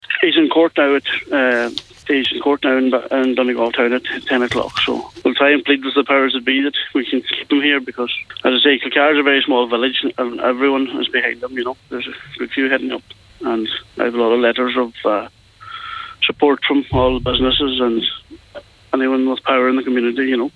has been giving an update of the situation on today’s Nine Till Noon Show: